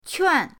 quan4.mp3